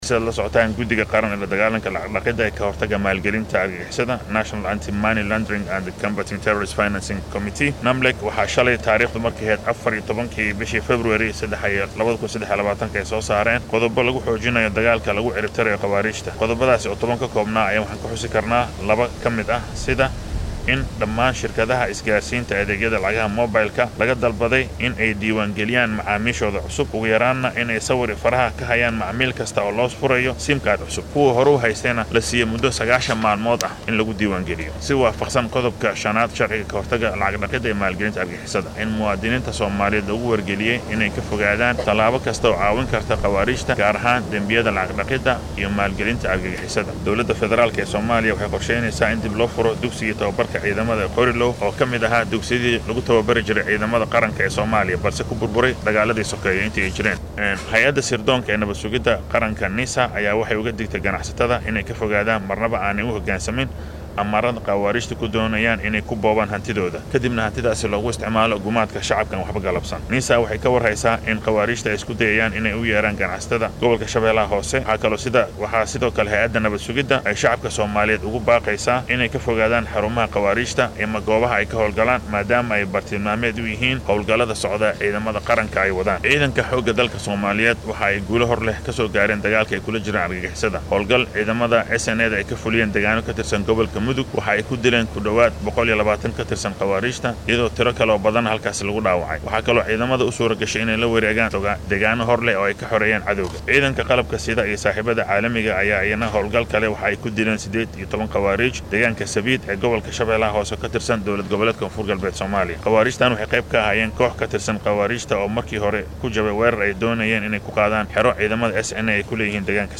Wasiirka wasaaradda warfaafinta ee xukuumadda dalka Soomaaliya, Daa’uud Aweys oo maanta shir jaraa’id u qabtay wariyeyaasha ayaa ka warbixiyay xaaladda guud